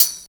50 TAMB   -R.wav